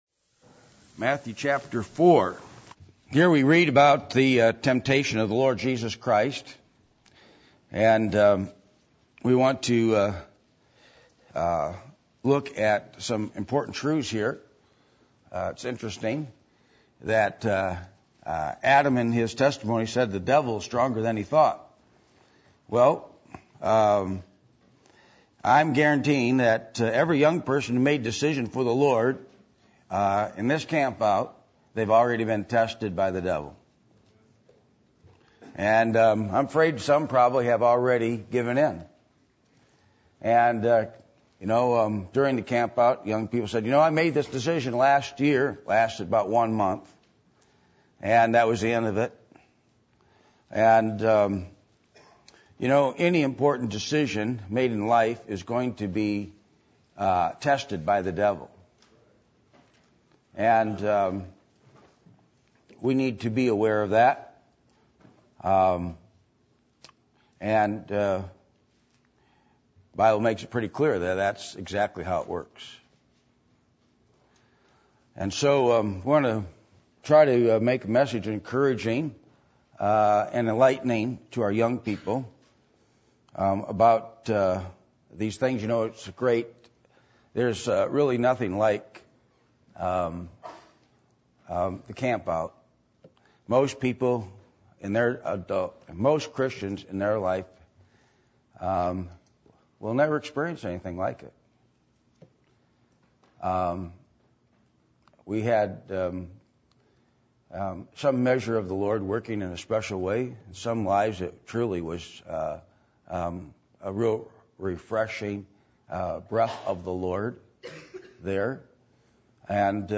Matthew 4:1-11 Service Type: Sunday Evening %todo_render% « Do You Have Religion Or Jesus?